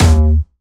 Jumpstyle Kick 1
11 D#2.wav